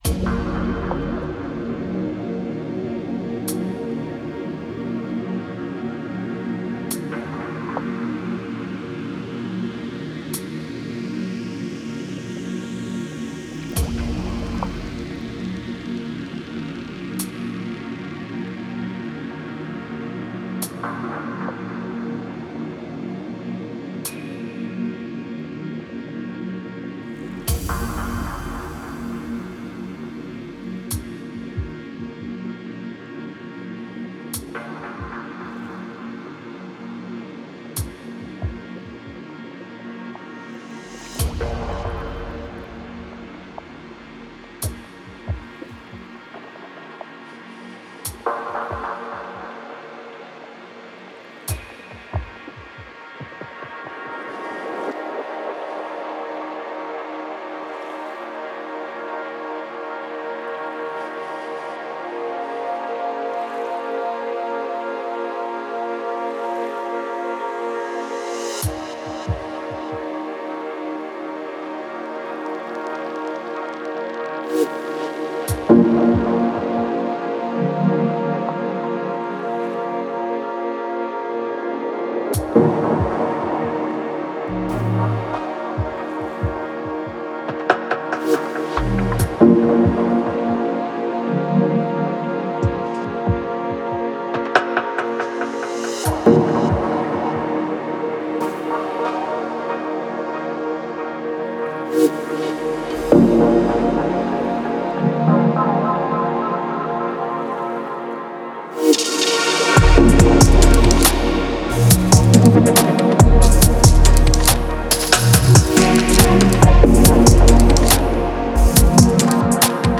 Genre: Deep Dubstep, Dub, Electronic.